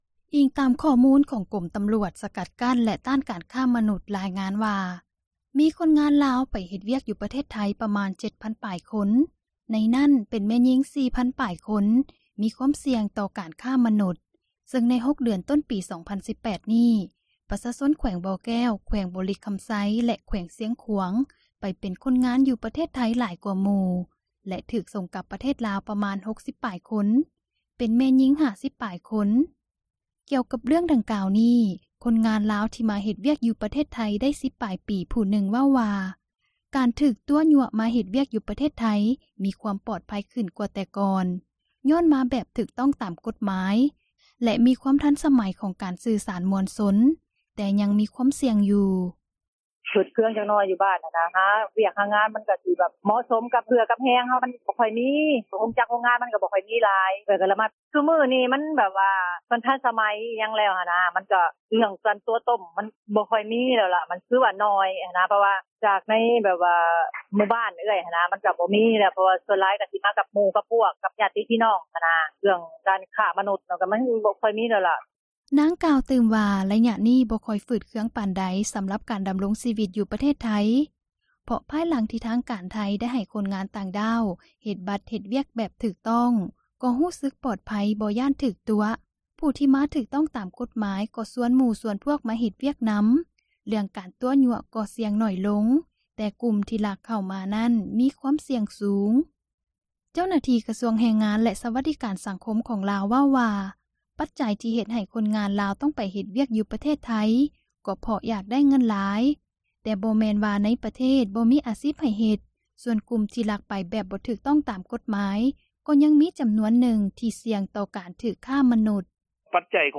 ຄົນງານລາວໃນໄທມີ ຄວາມສ່ຽງ — ຂ່າວລາວ ວິທຍຸເອເຊັຽເສຣີ ພາສາລາວ